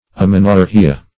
amenorrhea - definition of amenorrhea - synonyms, pronunciation, spelling from Free Dictionary
Amenorrhoea \A*men`or*rhoe"a\, Amenorrhea \A*men`or*rhe"a\ \ \